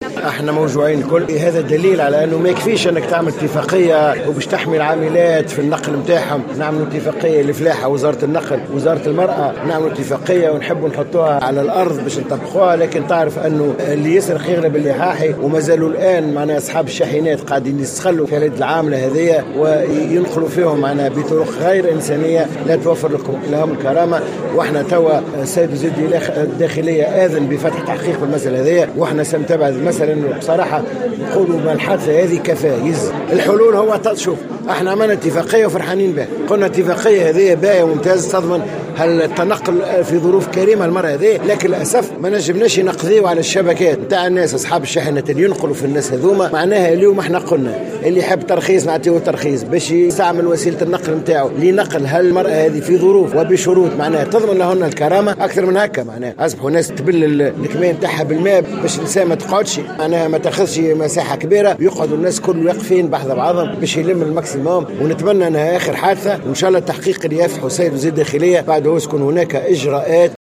قال وزير الفلاحة سمير الطيب معلقا على حادث السبّالة بسيدي بوزيد، إنه لا بد من الالتزام بالاتفاقيات الممضاة حول نقل عمال الفلاحة واتخاذ اجراءات صارمة.